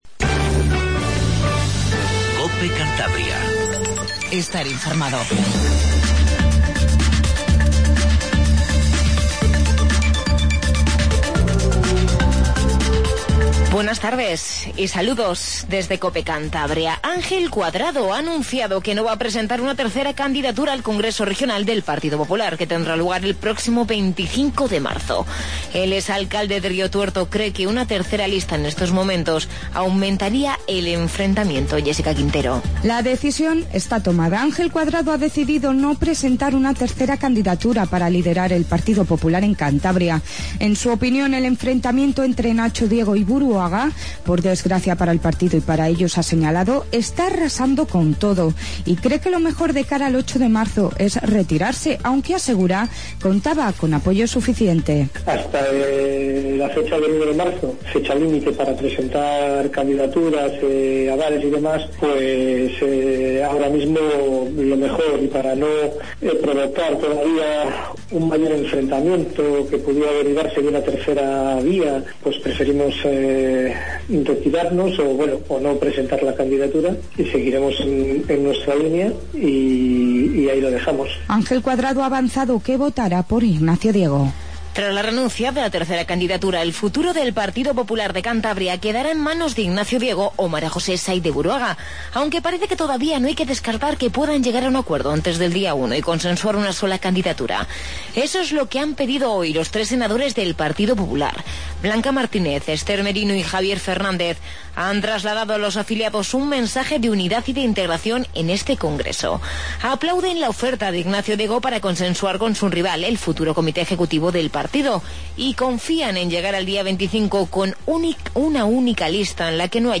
INFORMATIVO REGIONAL 14:50